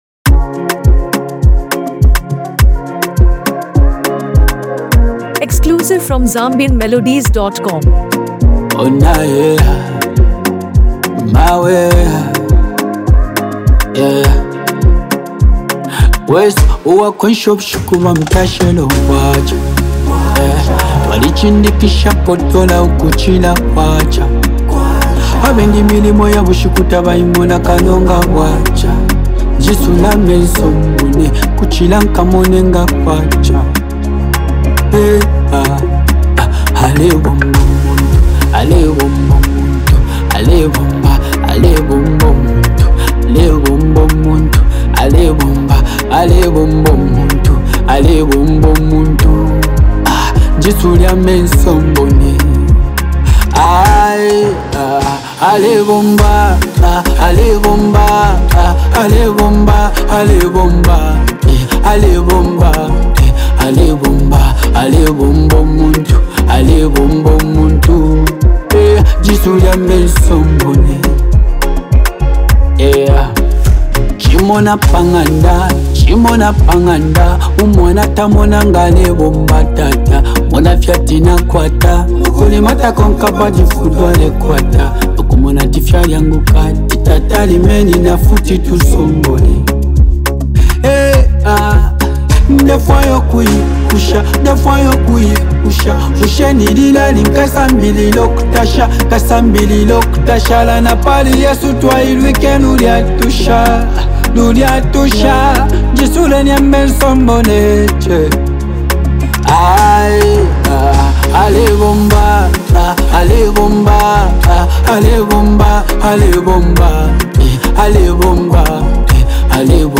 powerful and emotional track
Known for his deep storytelling and soulful delivery
smooth melodies